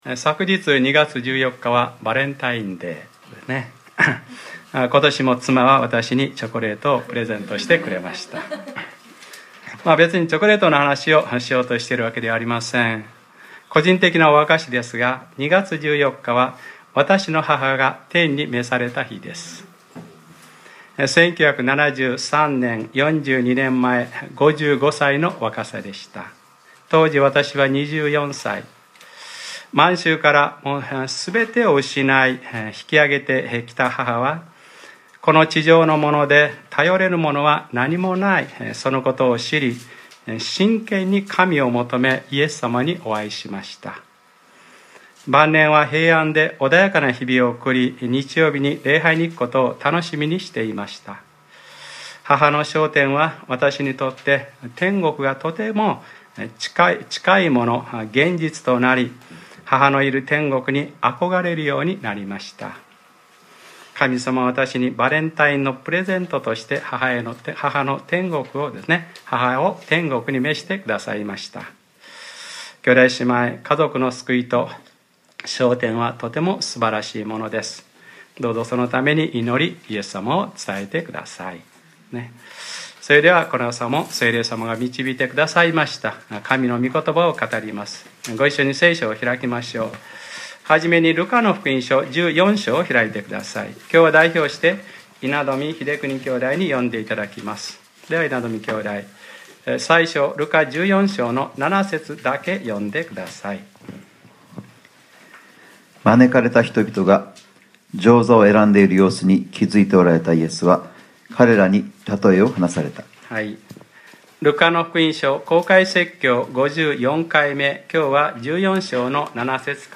2015年02月15日）礼拝説教 『ルカｰ５４：無理にでも人々を連れて来なさい』